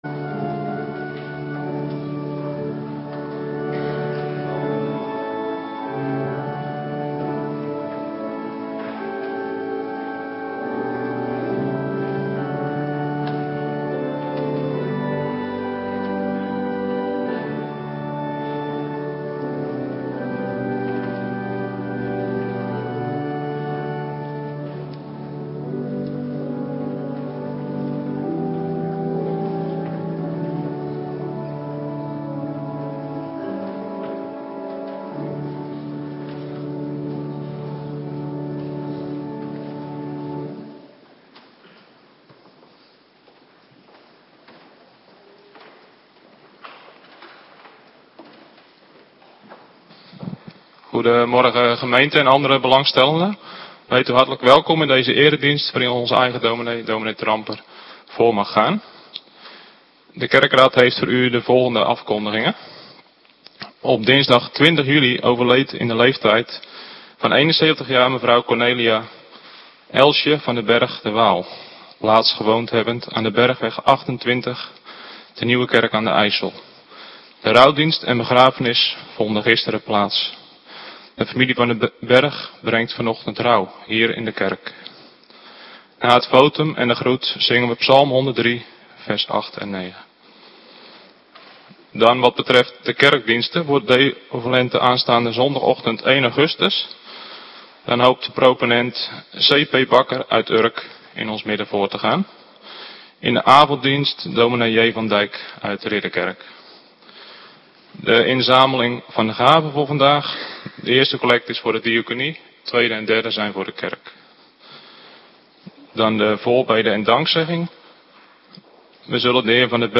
Morgendienst - Cluster B
Preek n.a.v. Handelingen 10: 44-46a Thema: Heil voor de heidenen (III)